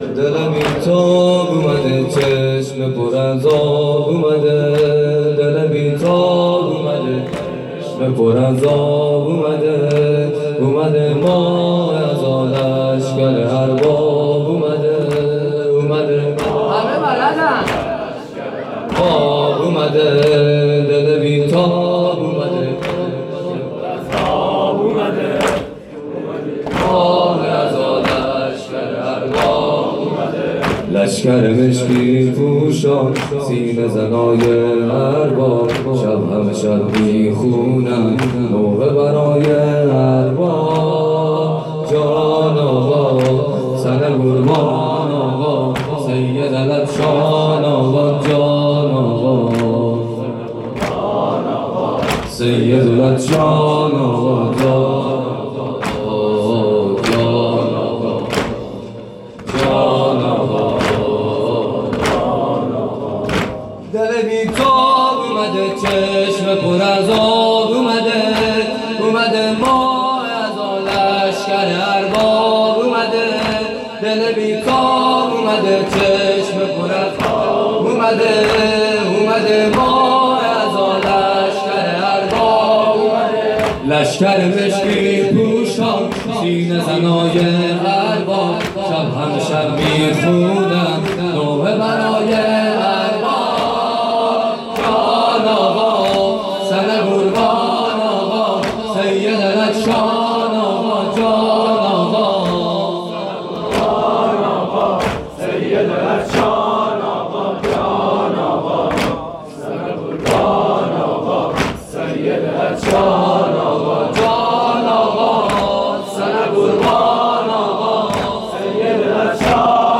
شب اول محرم ۹۷ هیئت صادقیون(ع)